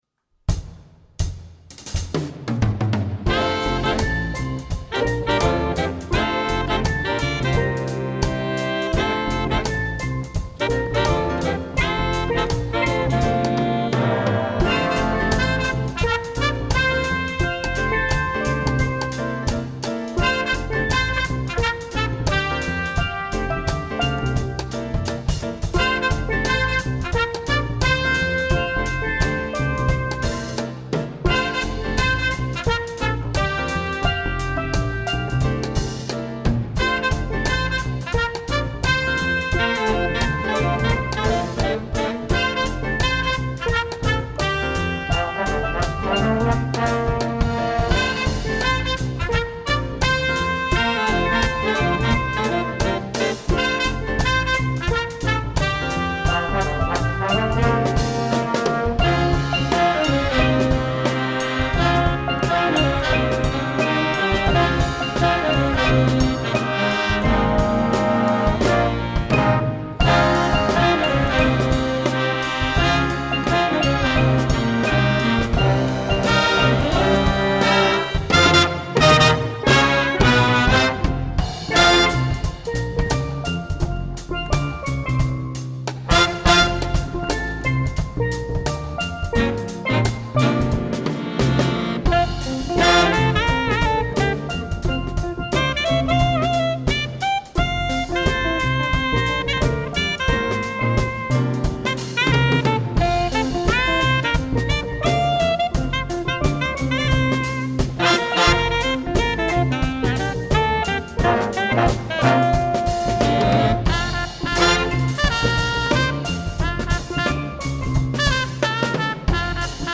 Style: Reggae
This is a jazz original in Reggae style!